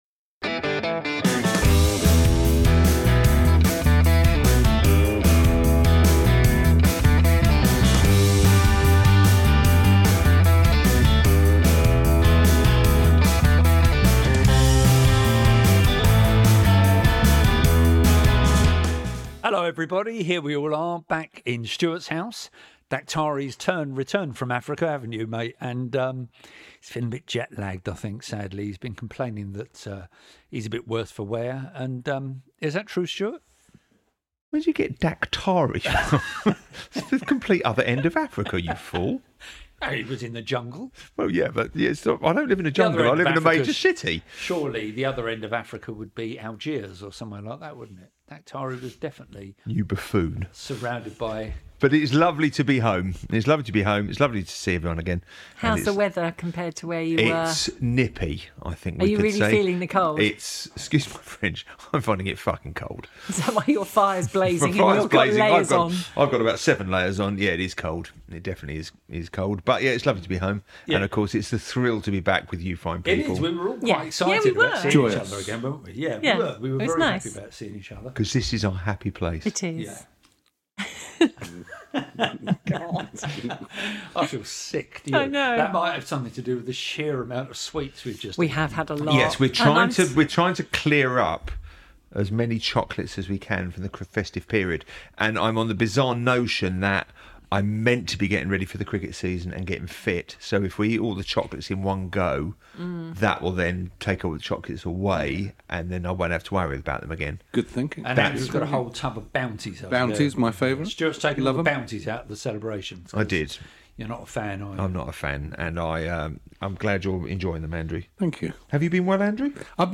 A return to HQ sees us covering Eastenders.......forty years ago, what's going on in the land of TV and film and who's been concentrating on the dental habits of the rich and famous! It's basically four well informed people (it says here) keeping you entertained for an hour or...